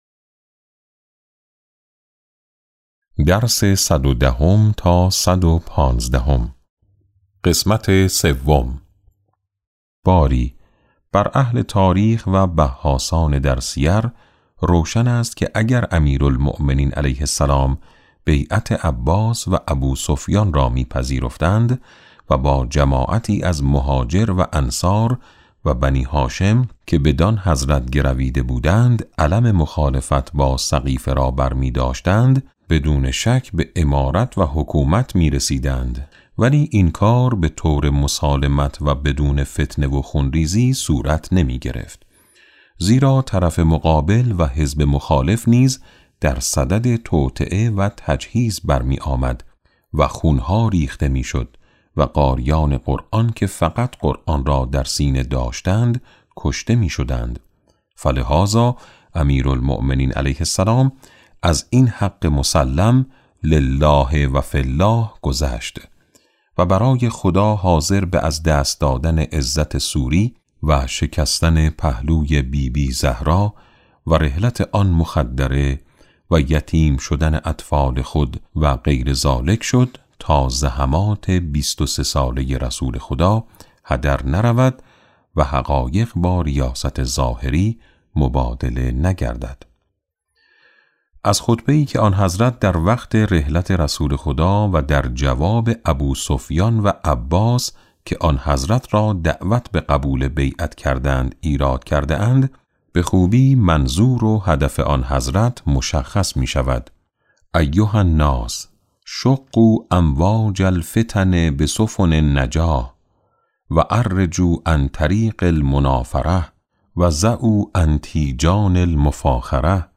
کتاب صوتی امام شناسی ج۸ - جلسه6